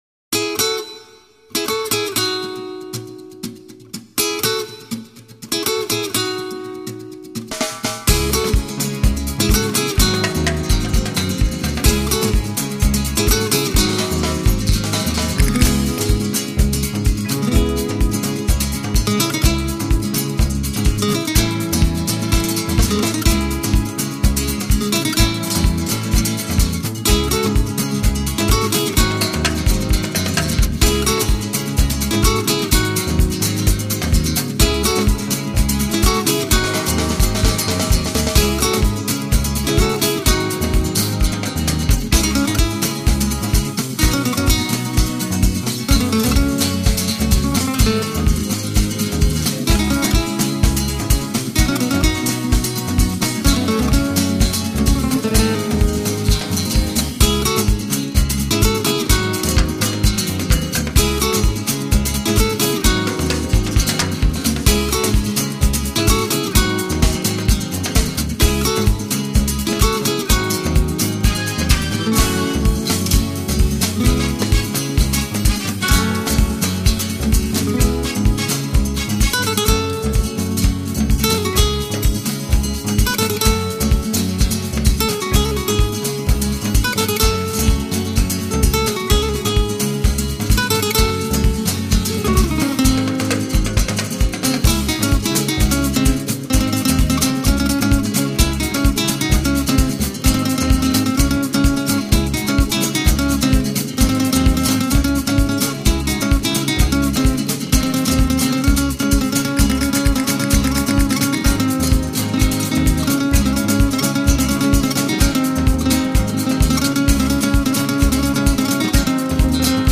音乐类型: Flamenco